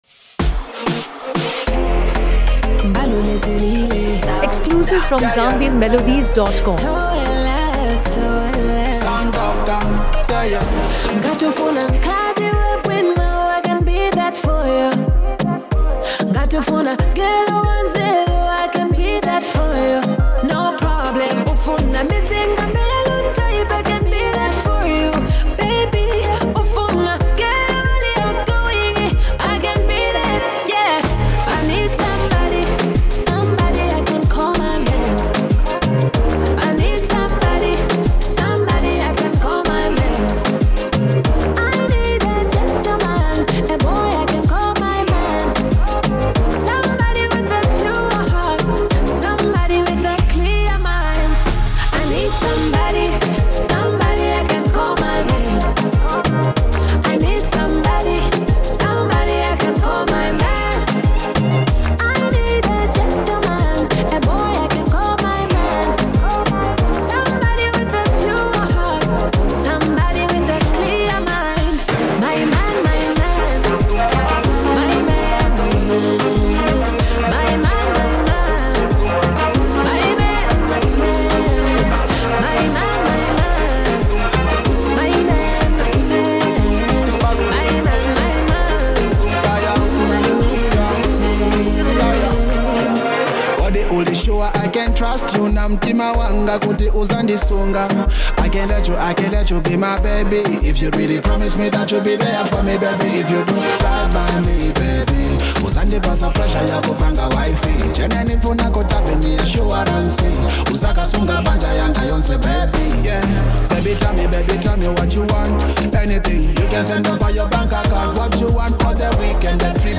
blends Afrobeat and pop
Genre: Afro-Pop